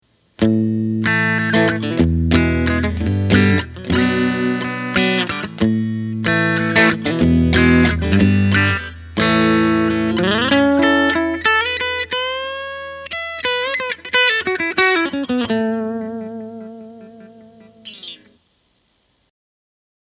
In any case, here are six versions of the same phrase with each different configuration:
With the Neck 2 setting, I get a similar sound to the neck/middle setting of a Strat, which is jangly.